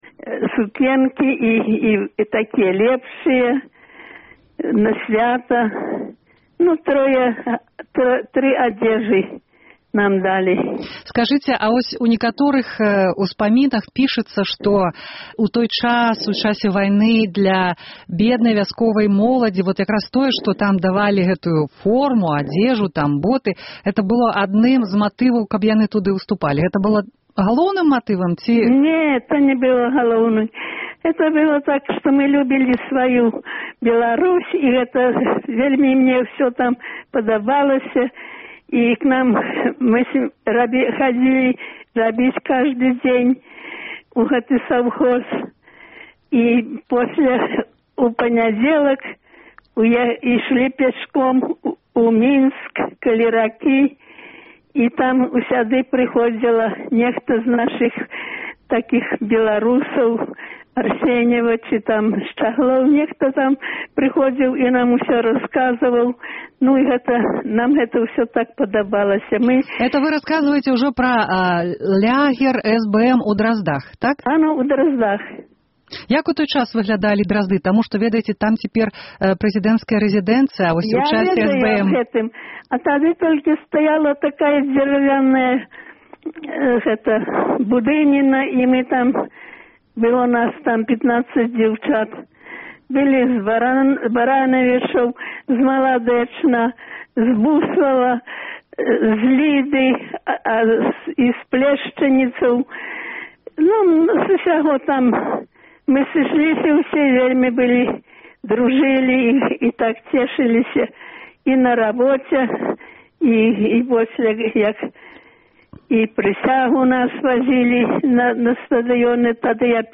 Спэцыяльная перадача, прысьвечаная 70-й гадавіне стварэньня Саюзу Беларускай моладзі, якая прыпадае на сёньня.